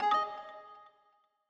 Longhorn XP - Exclamation.wav